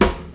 • Jazz Acoustic Snare Sample F# Key 52.wav
Royality free steel snare drum tuned to the F# note. Loudest frequency: 1244Hz
jazz-acoustic-snare-sample-f-sharp-key-52-n3u.wav